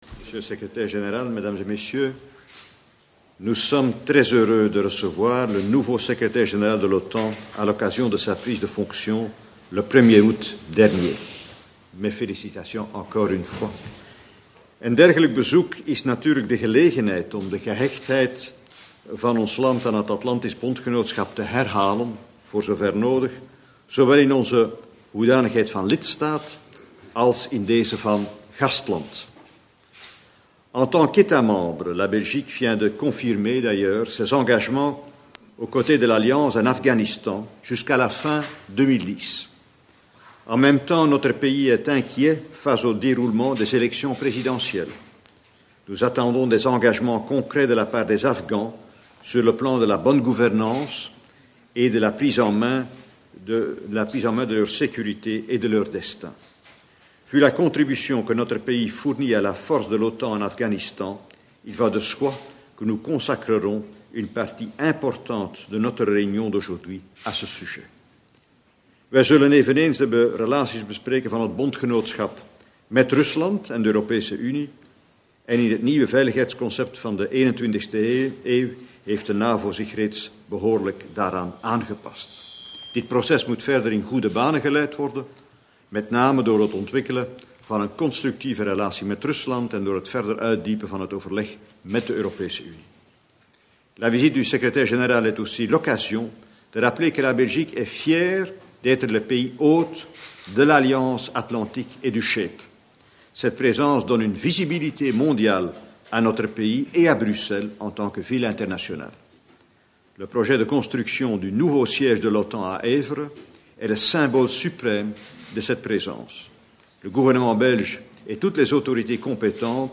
Press point with NATO Secretary General Anders Fogh Rasmussen and the Prime Minister of Belgium Herman Van Rompuy